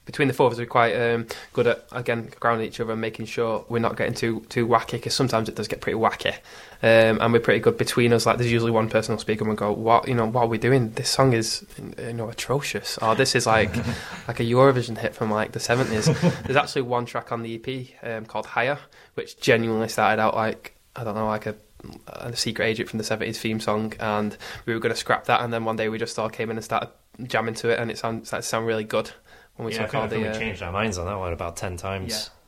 Project Aura Interview